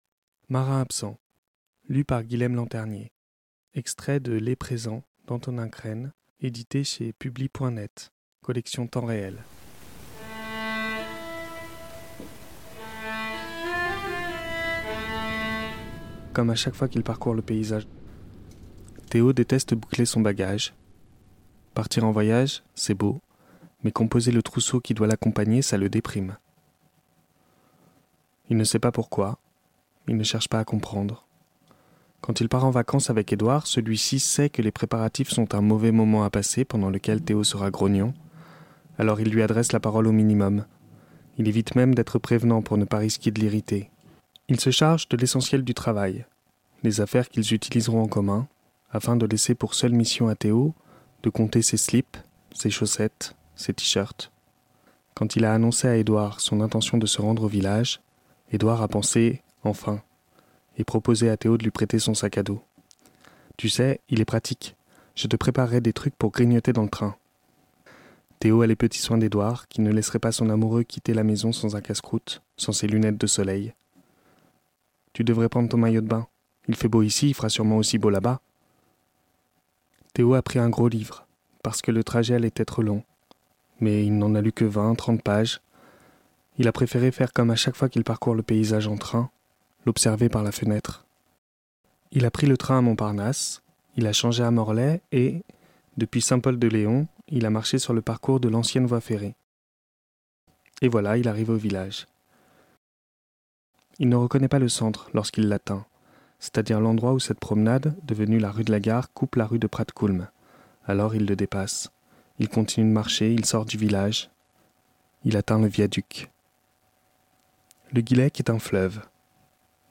Premier épisode de la lecture de Marin Absent, extrait du roman Les présents d'Antonin Cern.